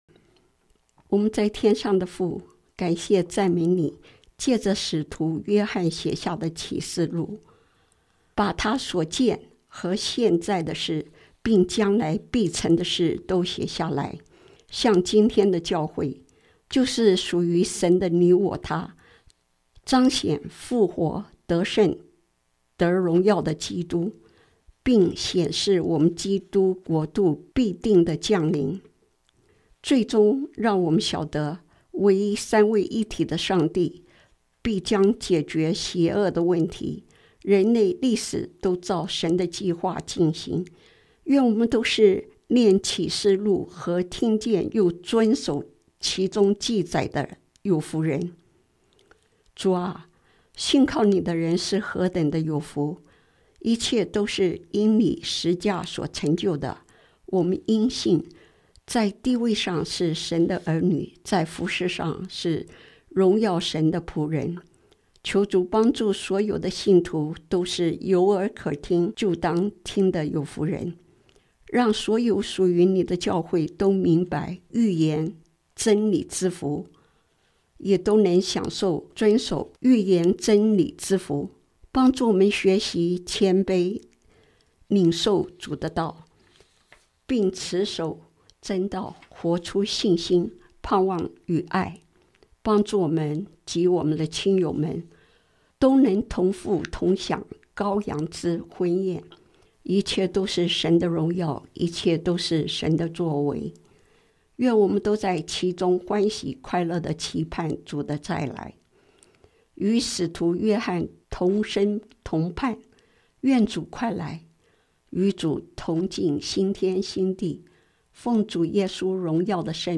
禱告詞